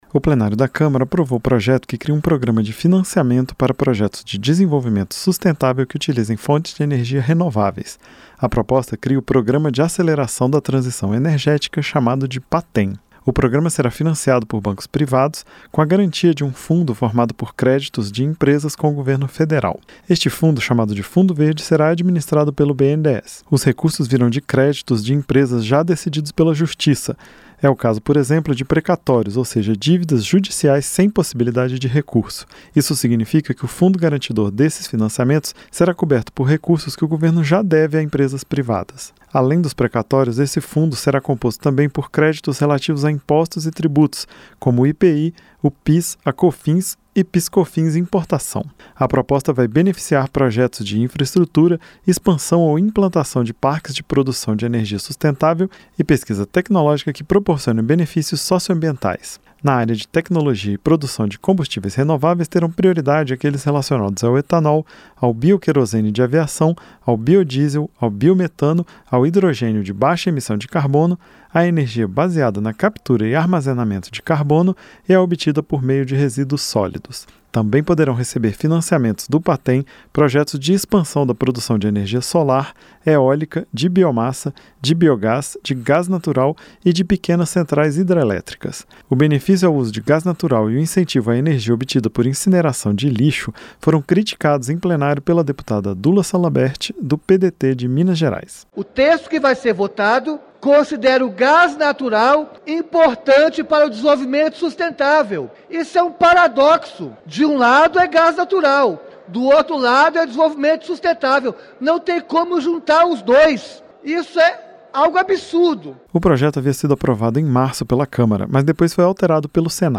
CÂMARA APROVA PROJETO QUE CRIA PROGRAMA DE INCENTIVO A PROJETOS DE TRANSIÇÃO ENERGÉTICA. O REPÓRTER